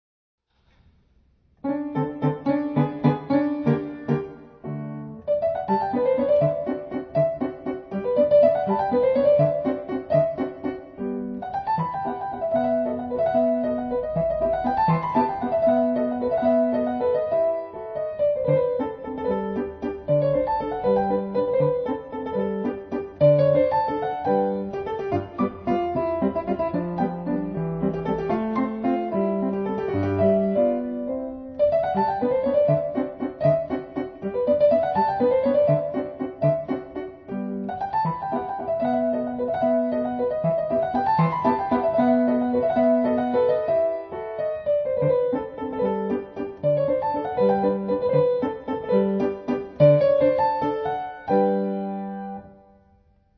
ピアノコンサート２
自宅のGPによる演奏録音 　　 デジピによる演奏録音